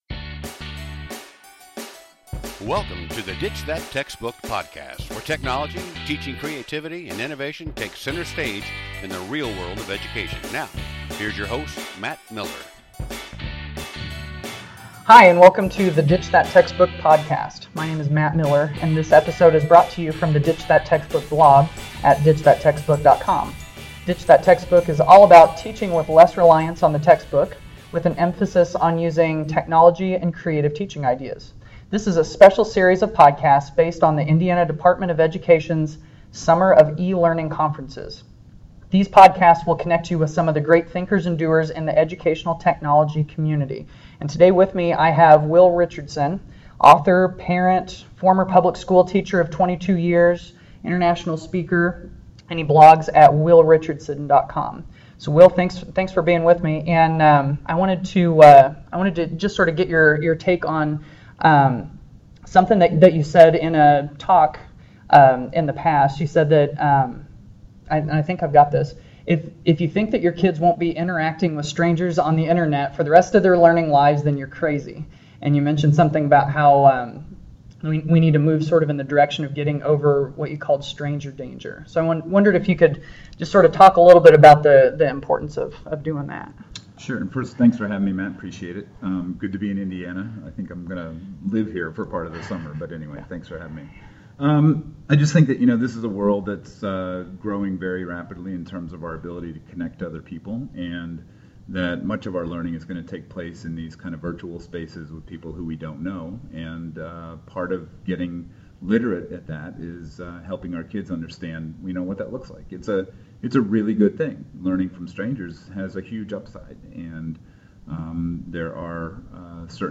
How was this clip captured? The Peace, Love and EdTech Conference at Southmont High School this week has been a thought-provoking and toolbox-building experience.